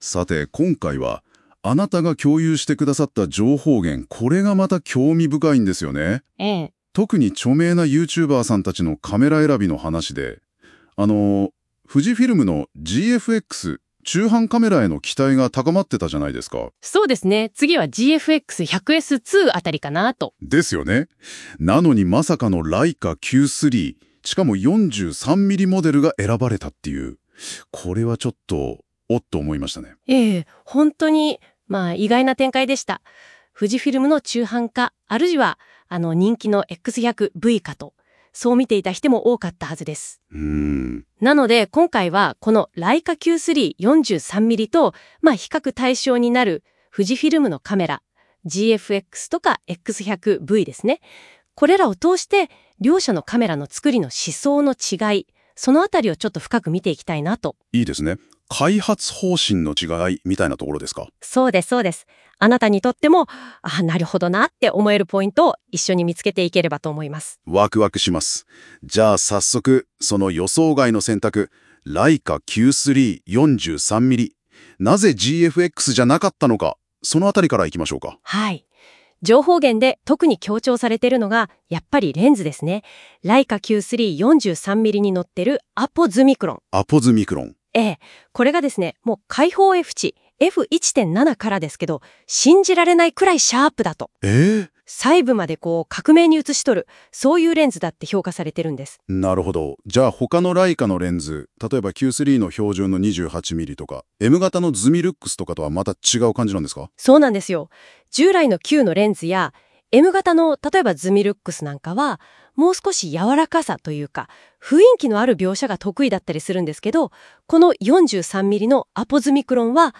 NotebookLMのオーディオにカスタム・プロンプトを入力。